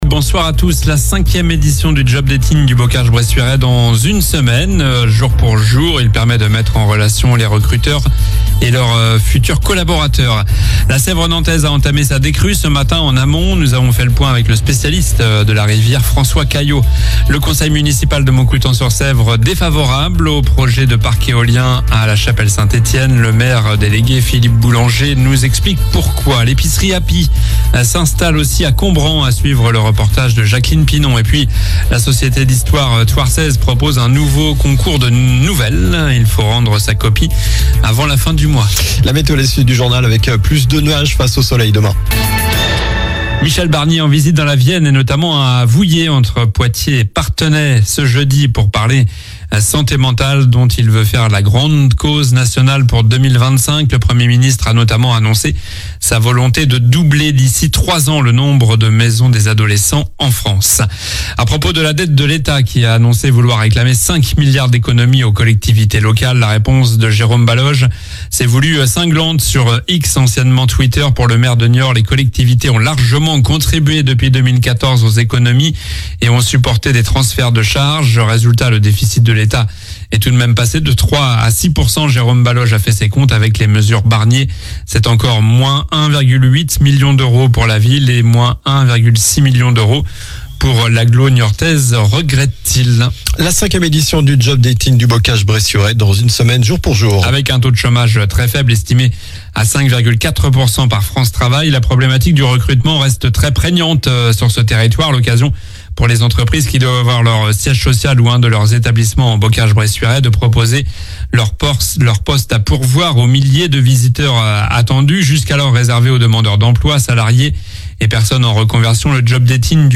Journal du jeudi 10 octobre (soir)